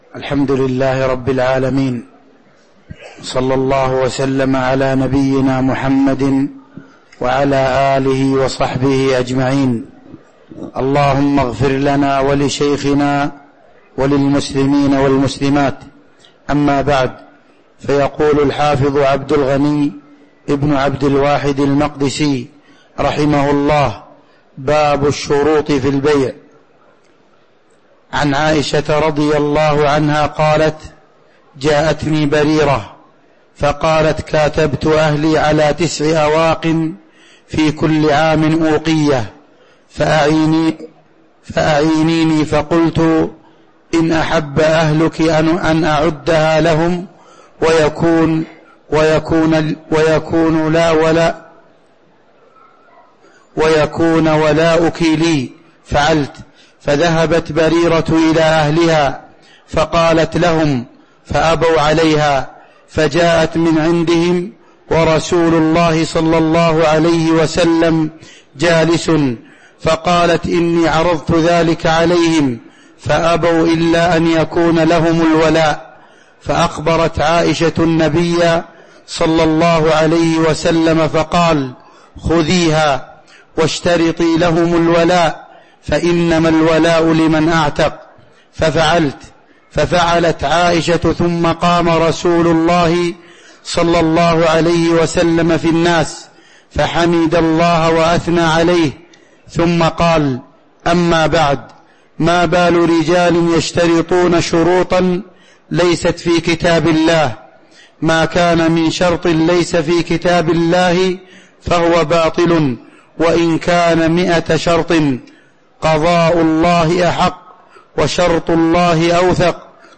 تاريخ النشر ٤ رجب ١٤٤٤ هـ المكان: المسجد النبوي الشيخ